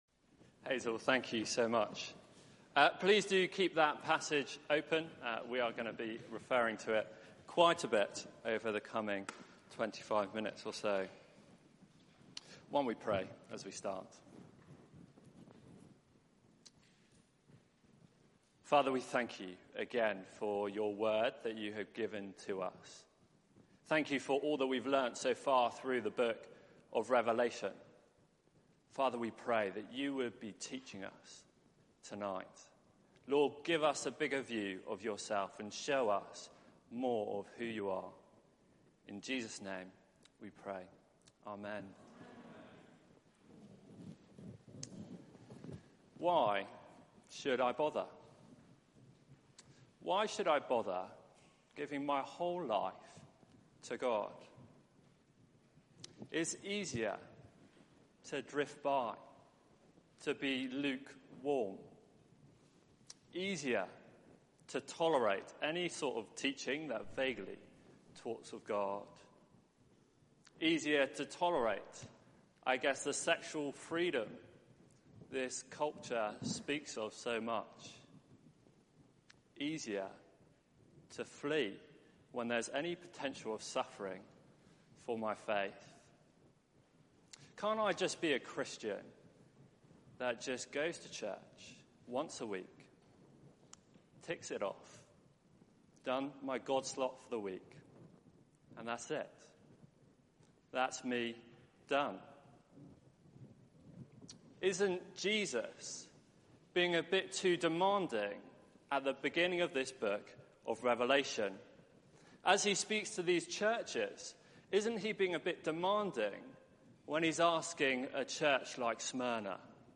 Media for 6:30pm Service on Sun 02nd Jun 2019 18:30 Speaker
Rev 4 Series: The Lamb Wins Theme: The One on the Throne Sermon Search the media library There are recordings here going back several years.